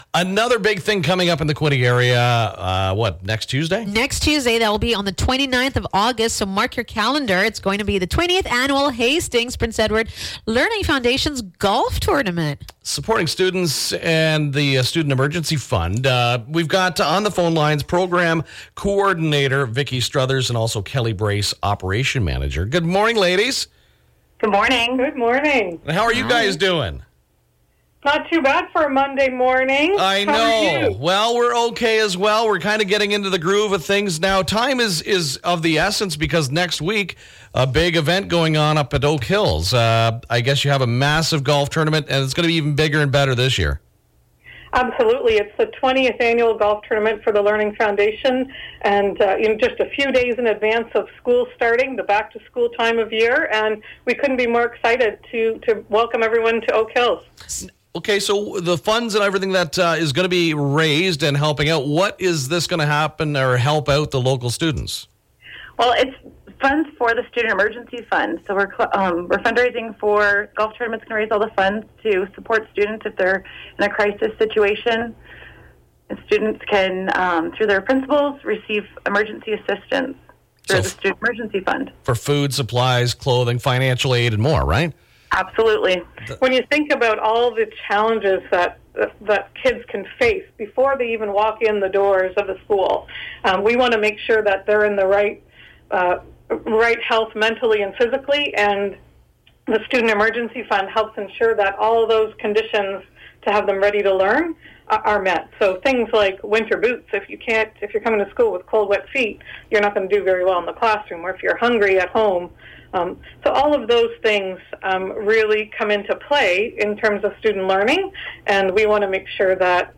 Its Monday morning and the MIX Morning Show have special guests in the studio.